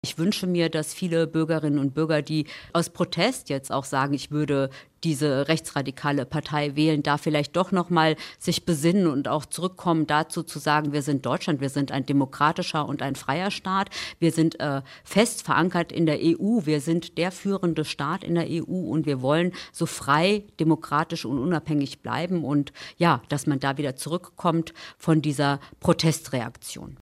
Deshalb haben wir unsere SPD-Bundestagsabgeordnete auf dieses Thema in unserem Radio Siegen-Sommer-Interview angesprochen.